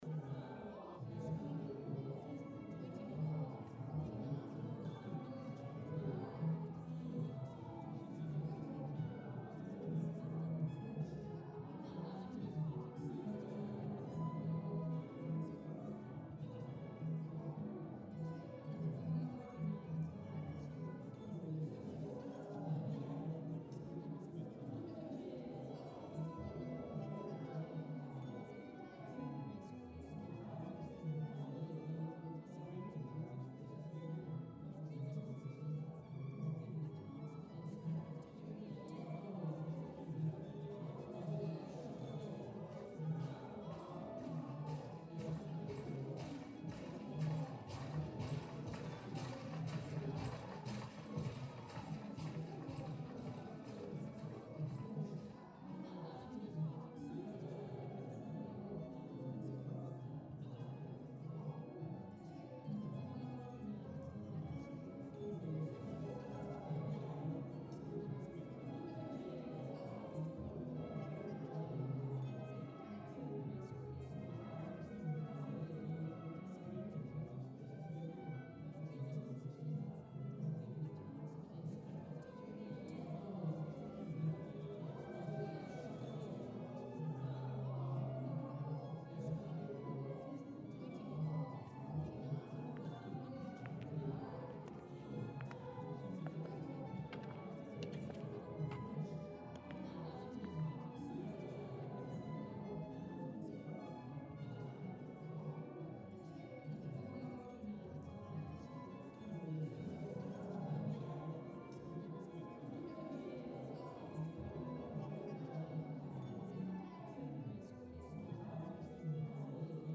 Nobles Mansion Corridors (Away).ogg